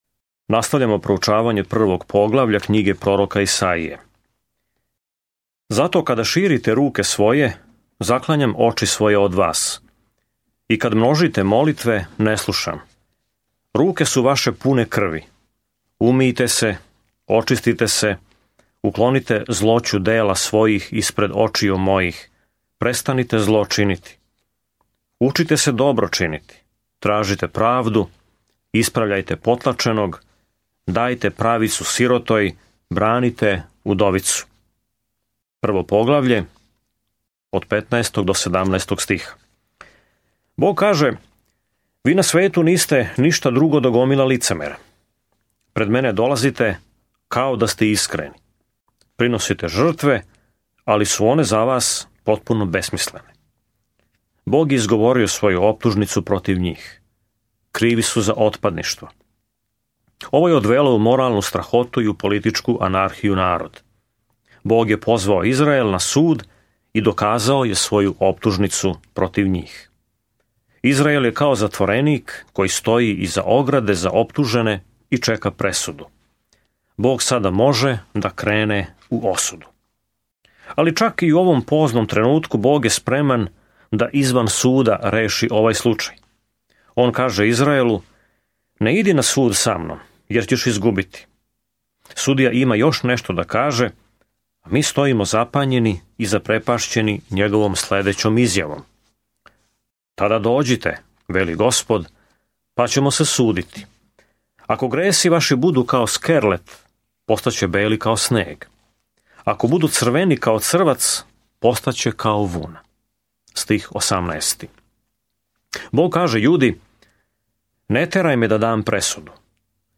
Sveto Pismo Knjiga proroka Isaije 1:15-31 Knjiga proroka Isaije 2:1-3 Dan 2 Započni ovaj plan Dan 4 O ovom planu Назван „пето јеванђеље“, Исаија описује долазећег краља и слугу који ће „носити грехе многих“ у мрачно време када ће политички непријатељи завладати Јудом. Свакодневно путујте кроз Исаију док слушате аудио студију и читате одабране стихове из Божје речи.